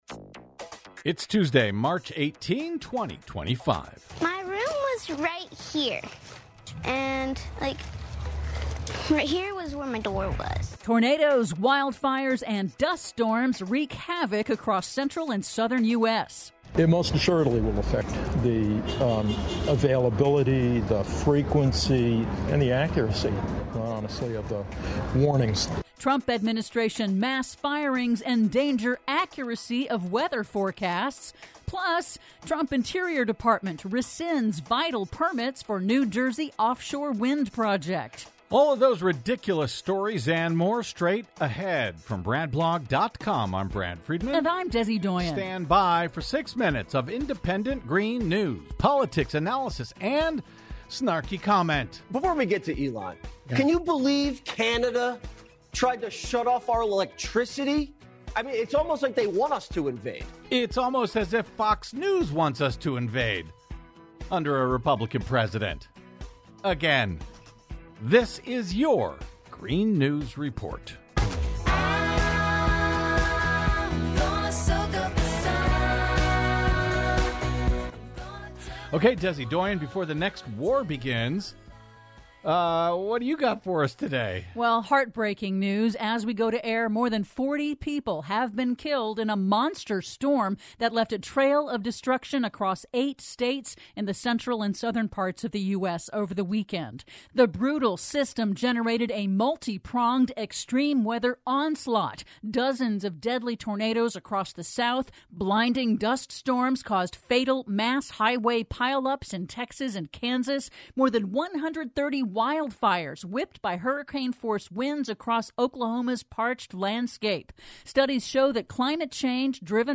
GNR's now celebrating 16 YEARS of independent green news, politics, analysis, snarky comment and connecting climate change dots over your public airwaves!